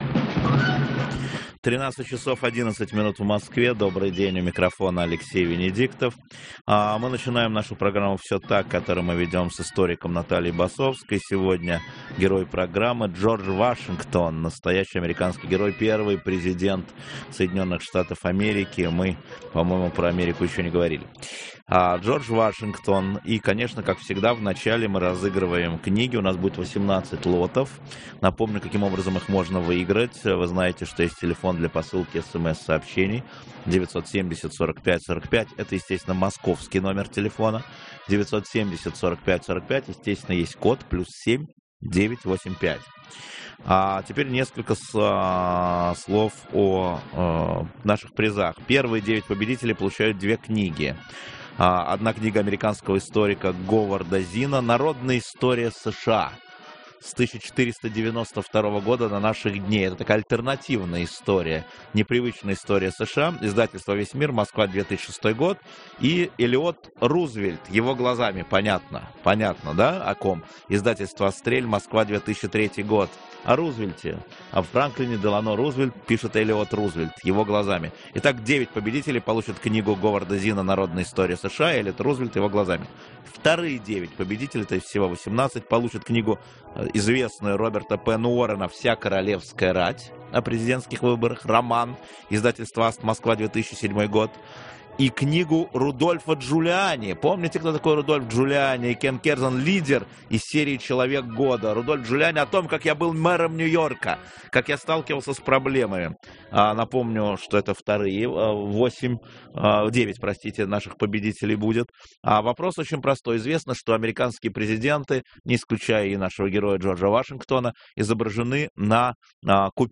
Добрый день, у микрофона Алексей Венедиктов. Мы начинаем нашу программу «Все так», которую мы ведем с историком Натальей Басовской. Сегодня герой программы Джордж Вашингтон, первый президент США.